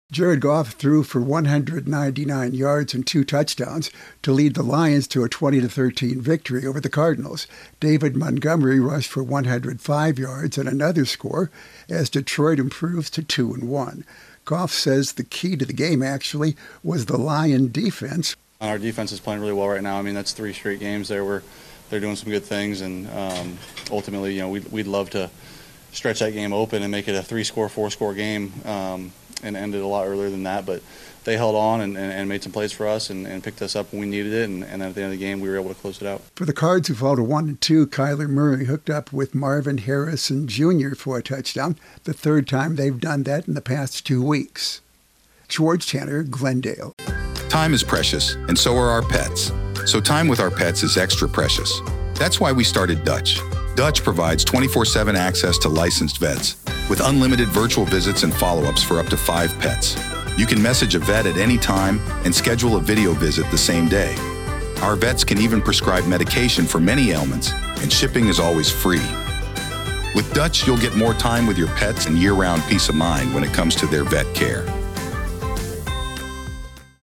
Jared Goff and David Montgomery lead the Lions' offense in a win over the Cardinals. Correspondent